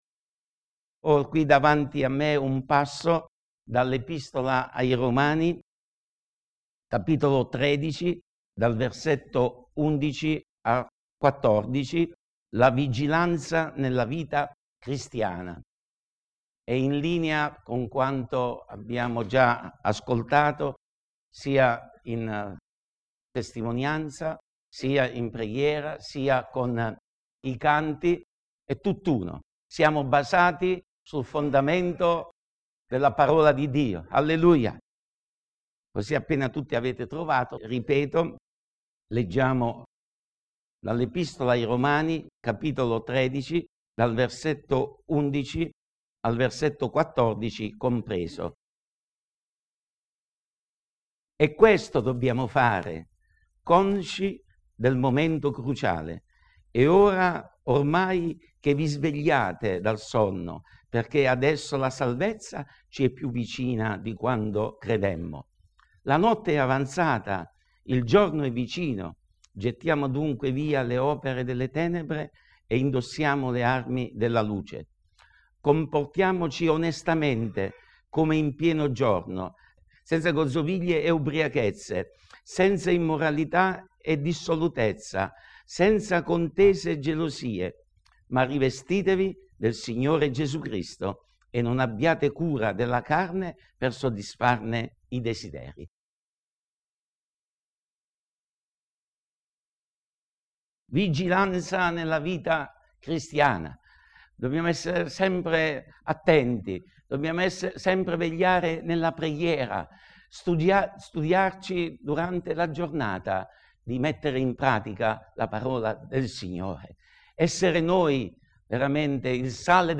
Predicazione 11 gennaio 2015 - La vigilanza nella vita cristiana